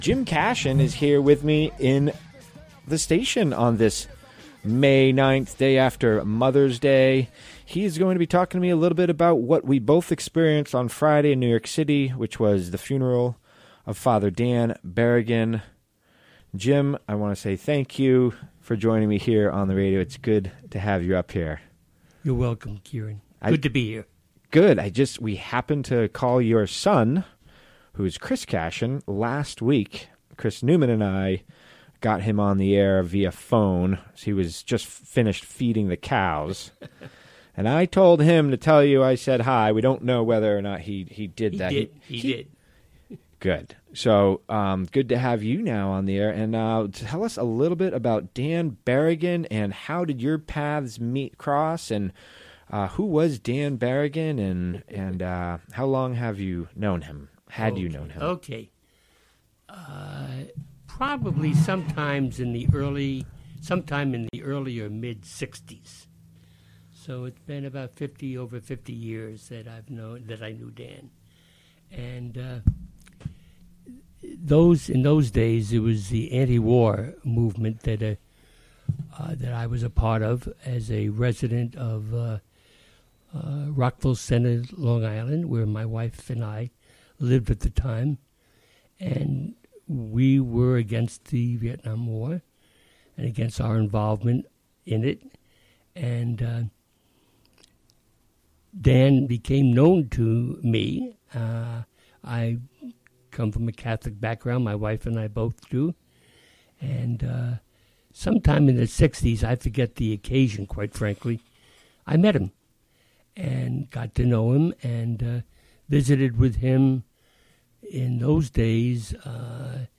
Interview from the WGXC Afternoon Show, Mon., May 9.